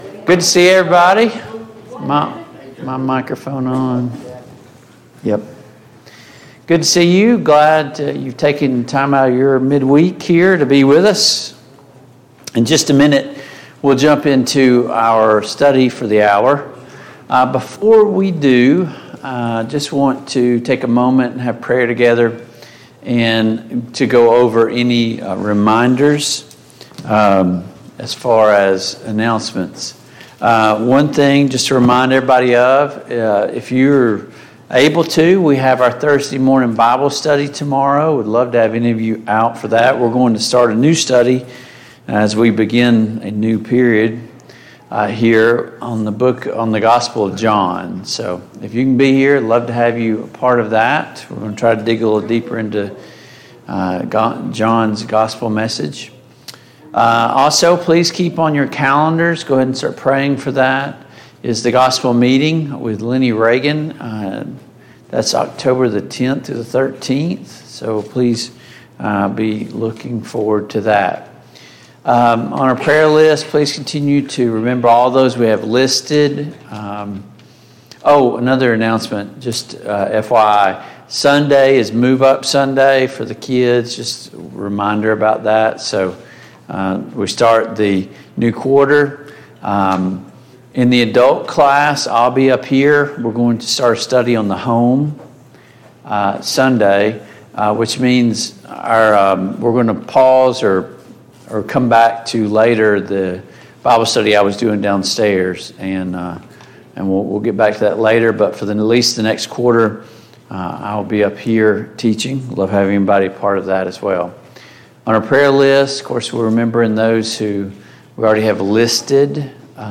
Mid-Week Bible Study Download Files Notes Topics: King Asa « The Blood of the New Covenant 1.